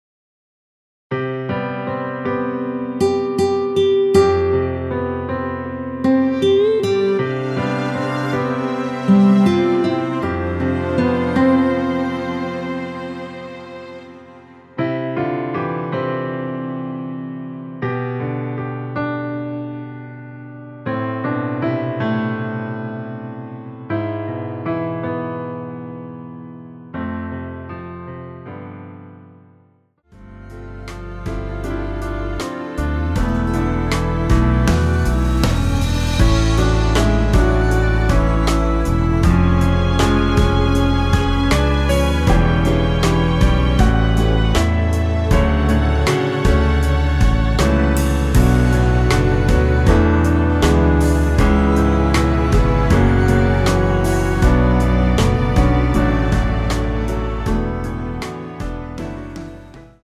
원키에서(-8)내린 MR입니다.
앞부분30초, 뒷부분30초씩 편집해서 올려 드리고 있습니다.
중간에 음이 끈어지고 다시 나오는 이유는